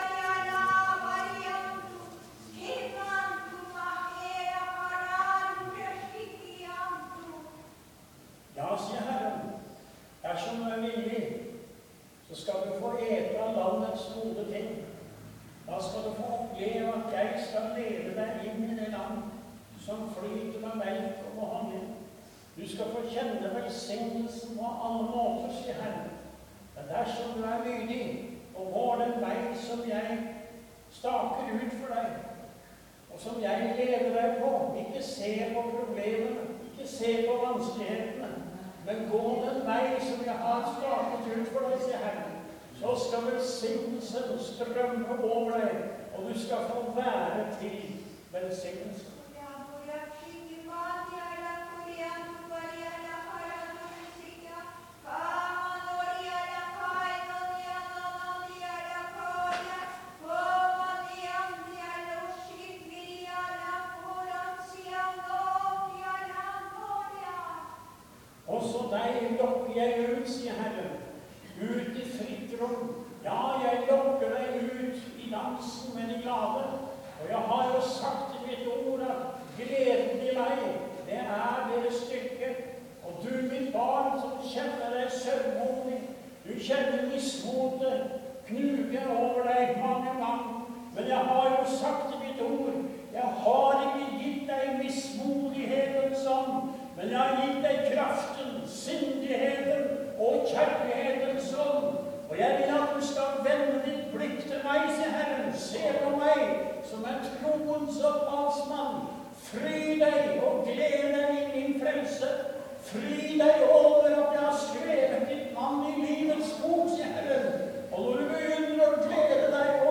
Jesus leier meg inn i det lova landet, eg skal gå den vegen som han stakar ut for meg. Pinsemøte i Maranata. 8.6.2014.
Ein mann song og spela og las frå Bibelen, han las mellom anna frå Johannes Evangeliet om lovnaden om den Heilage Ande og frå Jes.44,.3…: